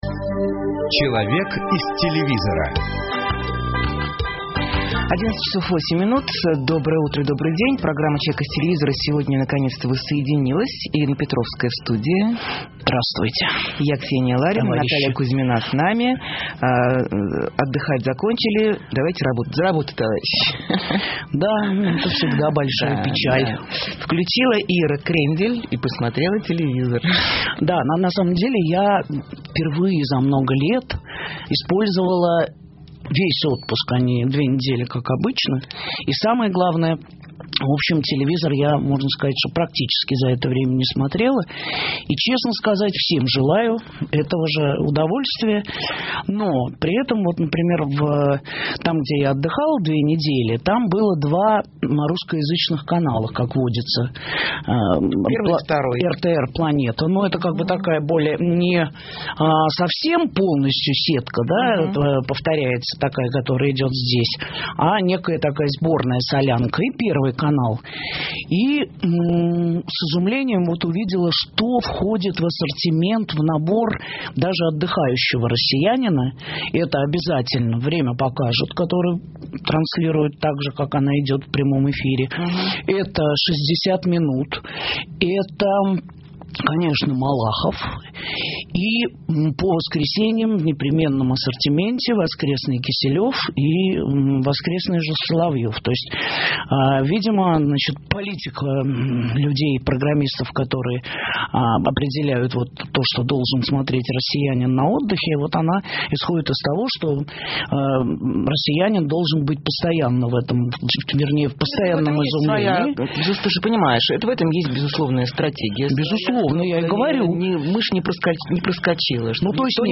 Программа «Человек из телевизора» сегодня, наконец, воссоединилась и Ирина Петровская в студии.